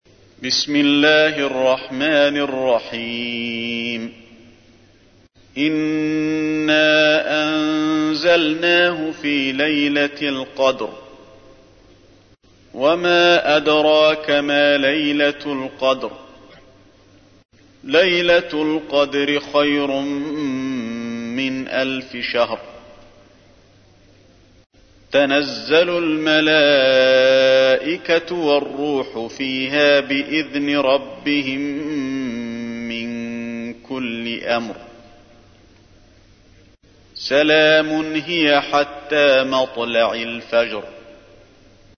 تحميل : 97. سورة القدر / القارئ علي الحذيفي / القرآن الكريم / موقع يا حسين